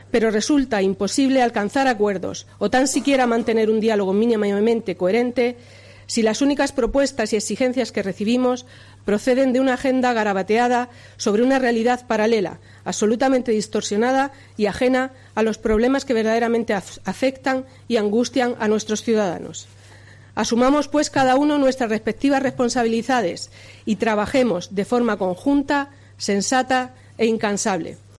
Estas declaraciones han sido realizadas en el acto de toma de posesión, sin opción a preguntas de los medios de comunicación, a los que se les ha negado tal posibilidad tanto antes como después de la comparecencia.